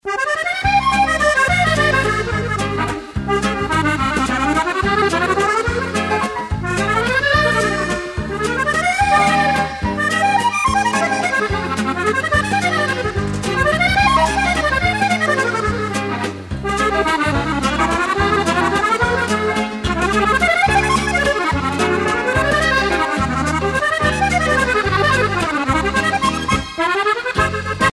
valse musette
Pièce musicale éditée